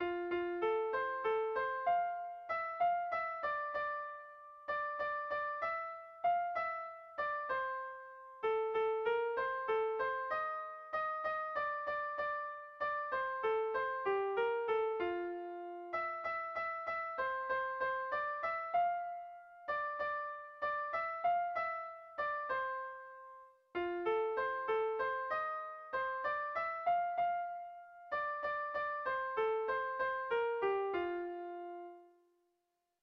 Irrizkoa
Zortziko handia (hg) / Lau puntuko handia (ip)
ABDE